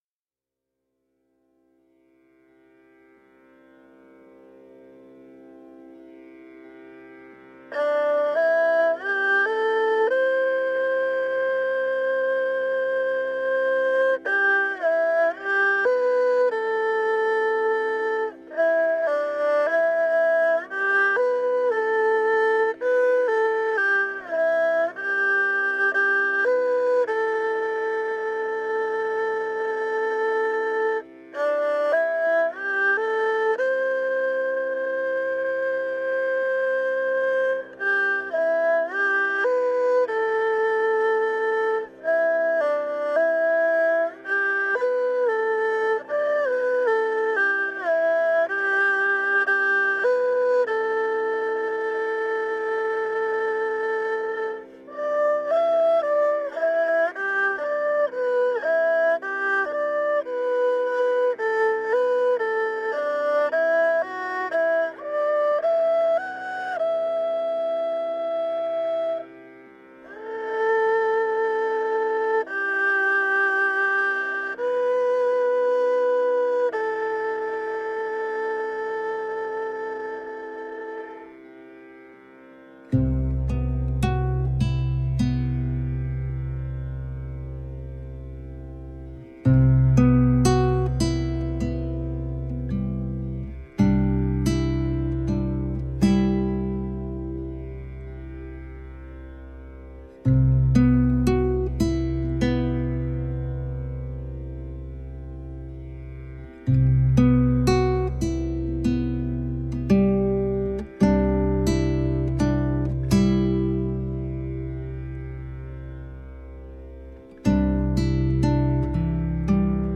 The album was recorded in Shanghai.
Tagged as: World, Instrumental World, Folk